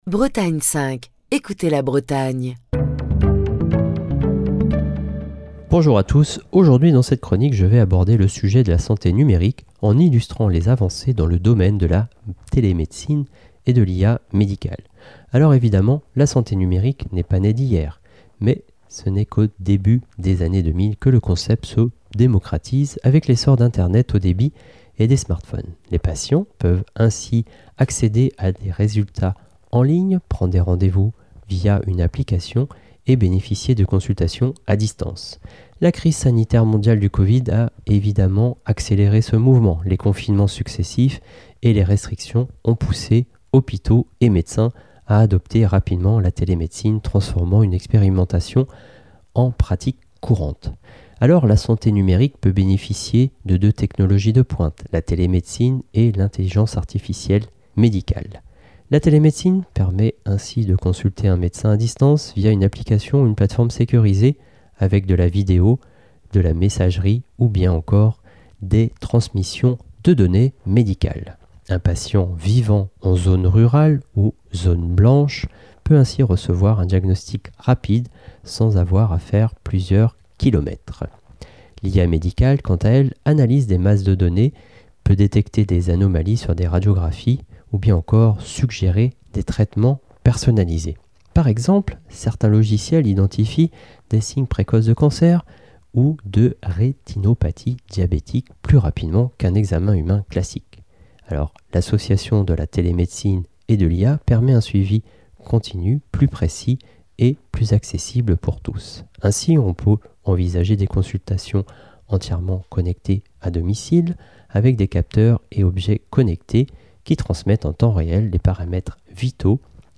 Chronique du 10 octobre 2025.